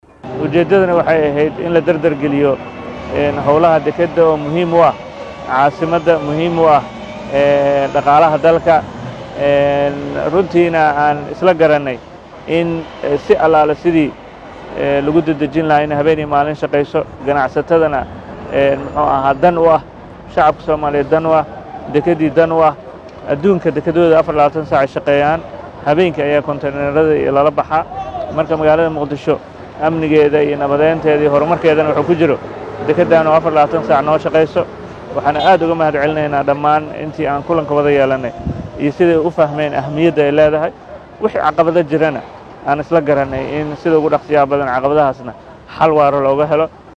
Hoos ka dhageyso codka guddoomiye Yariisow